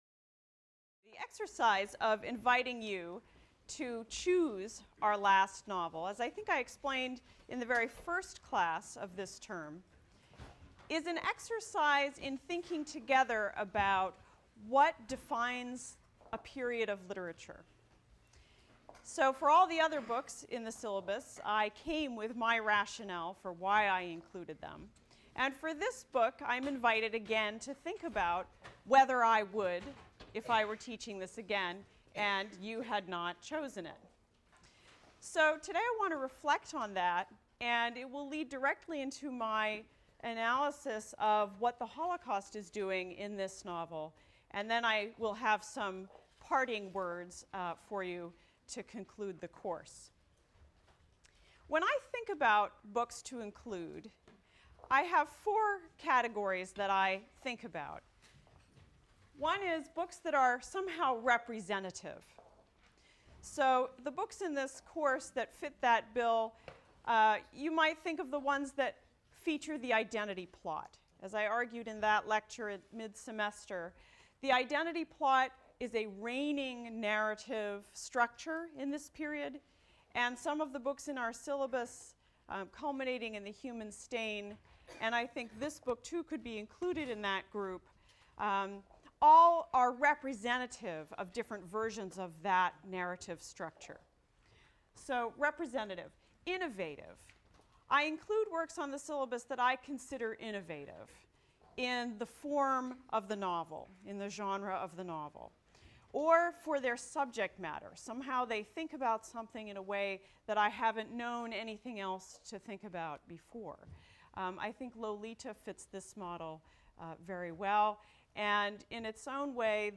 ENGL 291 - Lecture 25 - Students’ Choice Novel: Jonathan Safran Foer, Everything is Illuminated (cont.)